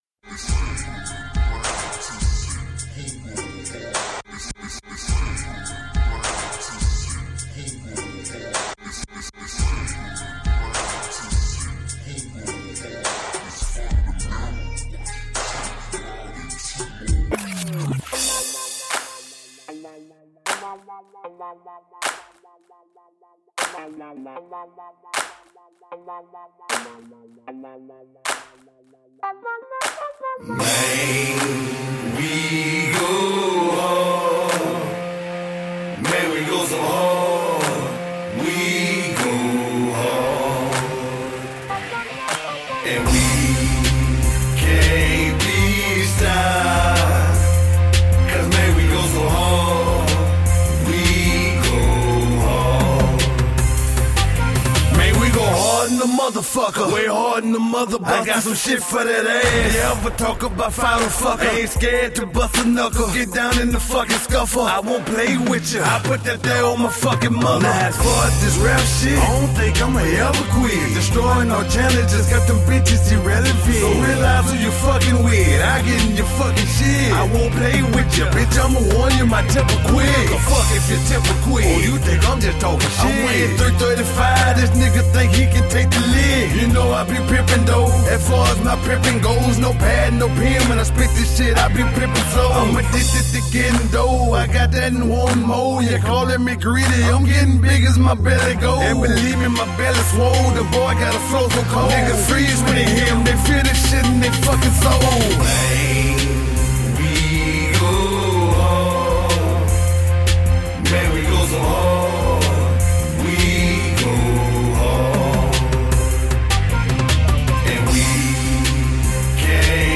Excellent new school feeling hip-hop.
This album has a darker mood